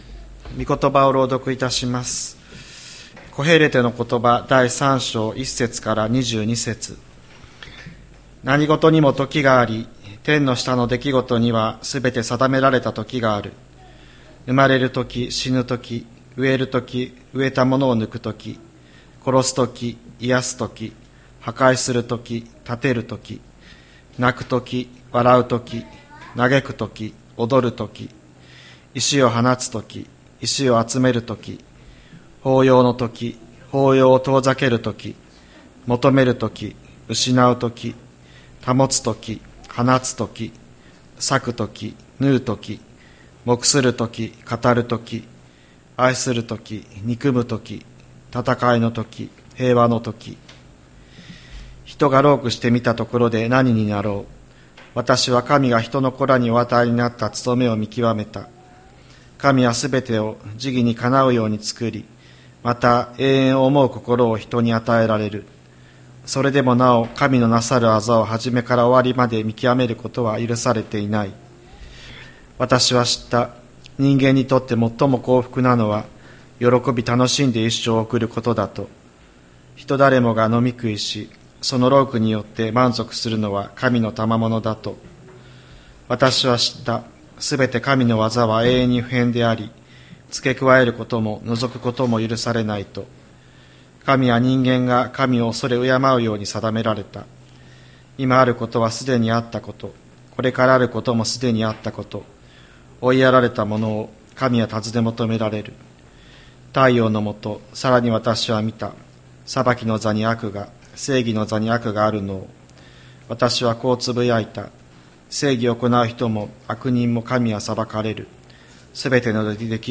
2024年05月26日朝の礼拝「何事にも時がある」吹田市千里山のキリスト教会
千里山教会 2024年05月26日の礼拝メッセージ。